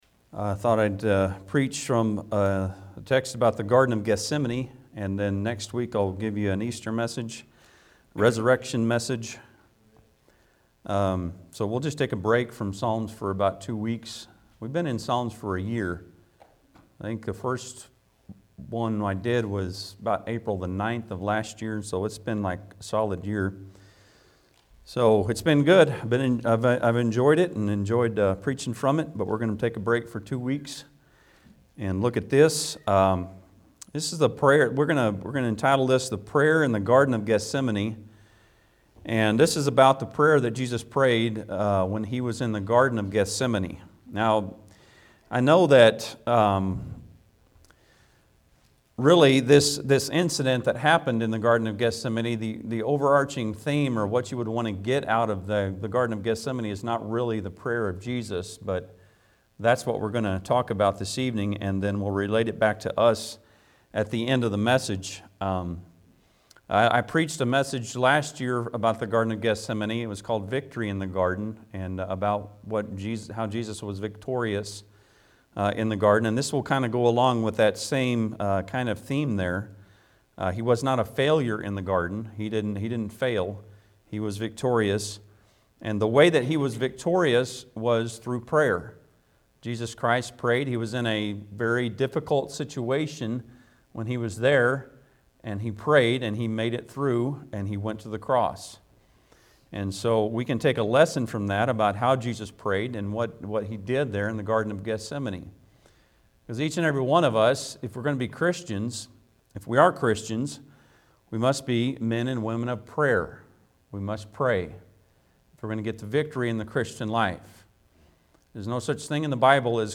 Passage: Luke 22:39-46 Service Type: Sunday pm Bible Text